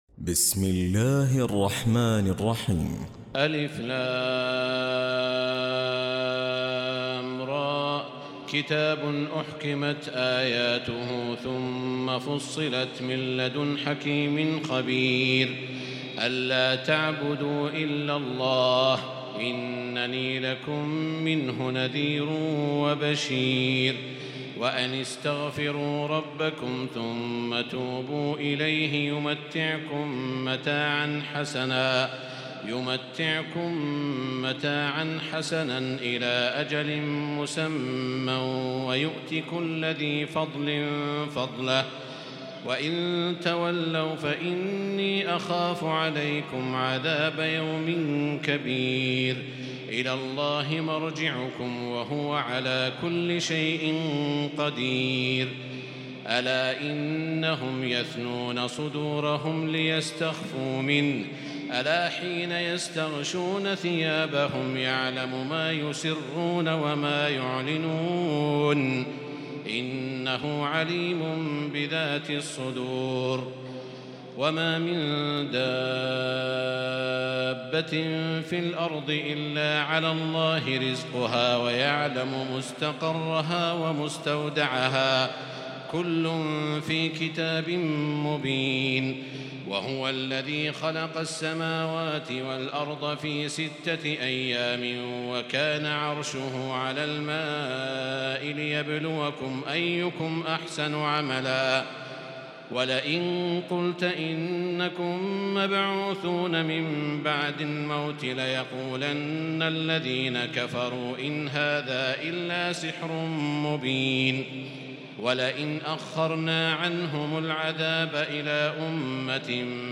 تراويح الليلة الحادية عشر رمضان 1438هـ من سورة هود (1-83) Taraweeh 11 st night Ramadan 1438H from Surah Hud > تراويح الحرم المكي عام 1438 🕋 > التراويح - تلاوات الحرمين